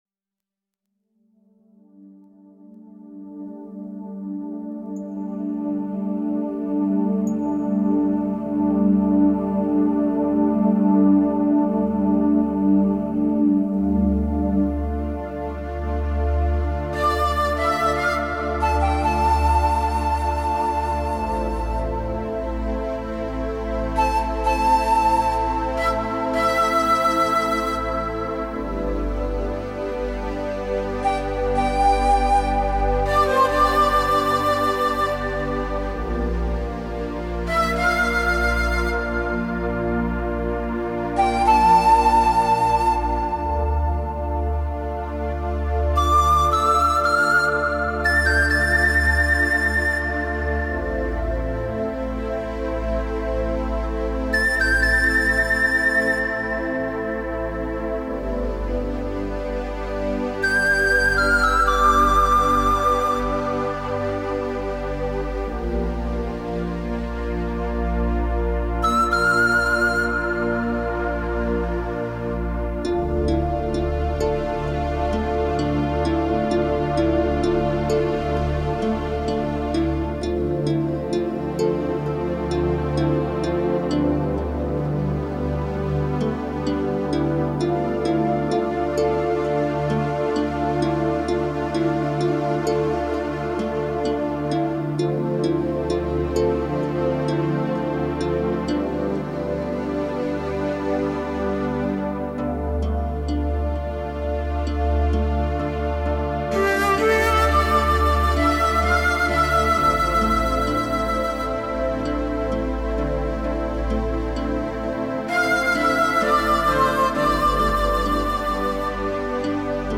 3周前 纯音乐 5